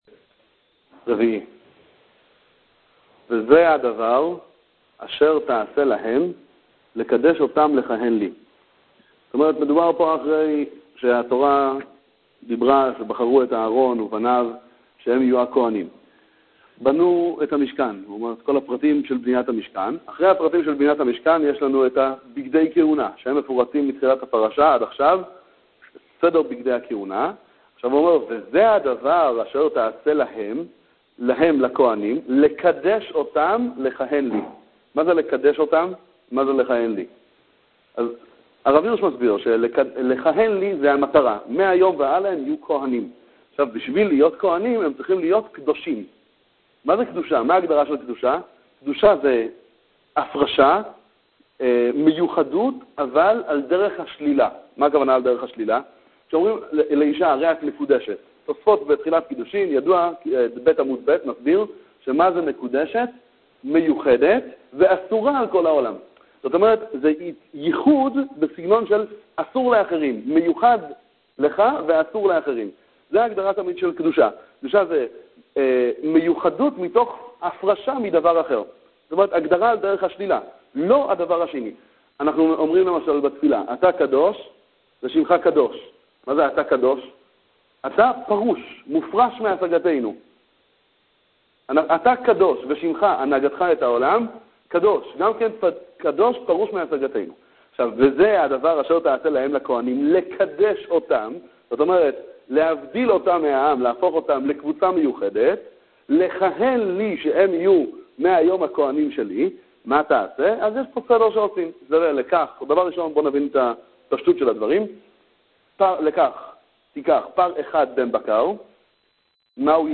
שיעורי תורה על פרשת השבוע